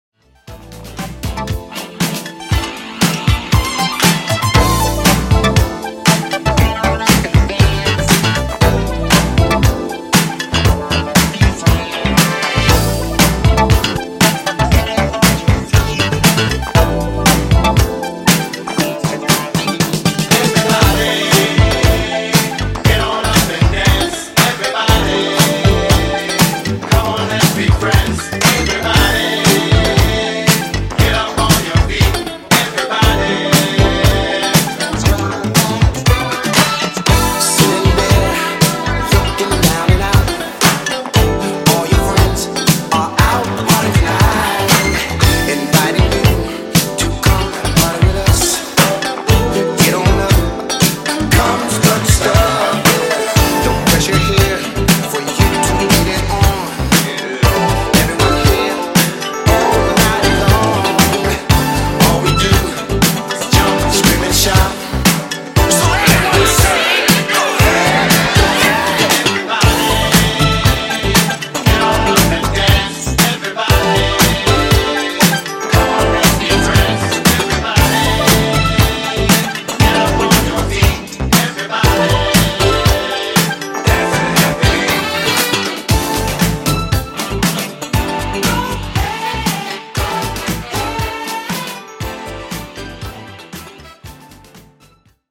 80s Funk Mashup)Date Added